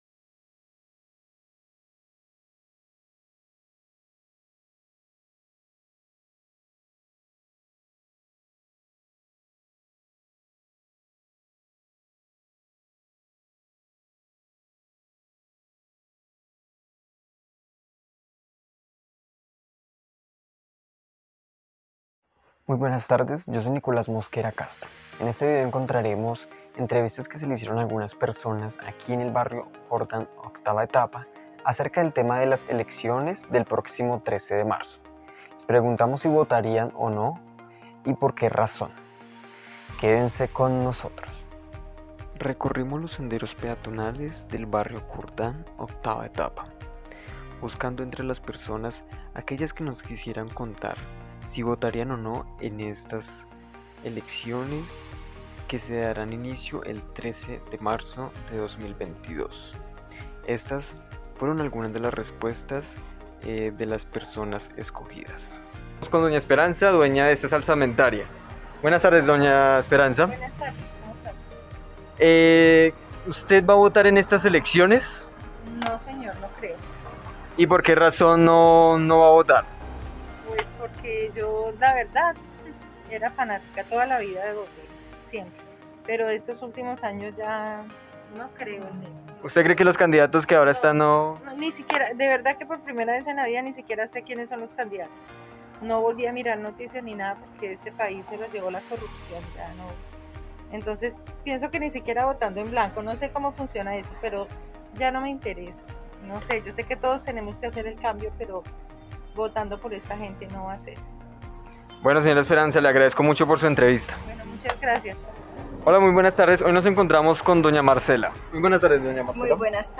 Se han realizado muchos sondeo de opinión para conocer por medio de encuestas en todo el país sobre el apoyo y el respaldo de las personas hacia algunos o varios candidatos, sin embargo, nuestro periodista hizo un voz populi para saber de primera fuente sobre la opinión de las personas si van a salir ha votar en las próximas elecciones 2022.